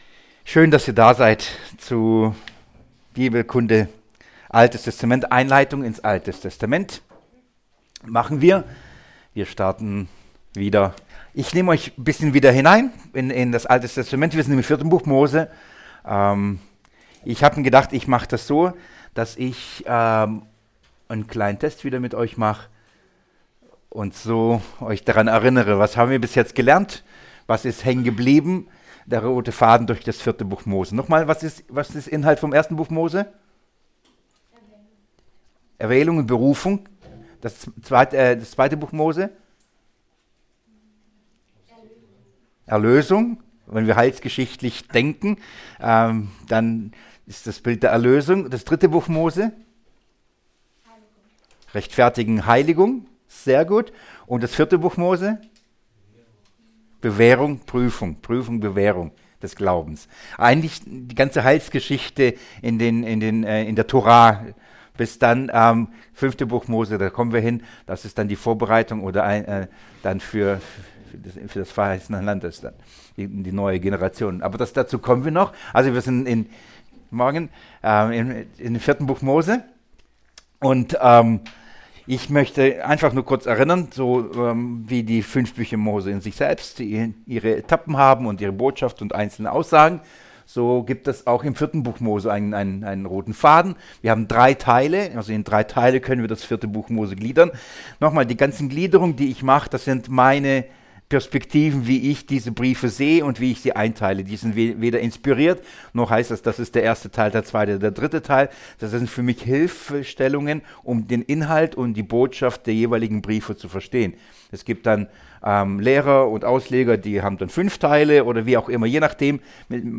Mose Ansehen Hören Herunterladen 4Mose 13: Wiederholung + Josua aus Ephraim Der 15. Tag dieses Seminars am Kreuzlicht-Seminar fand am 15.09.2018 in Heubach statt.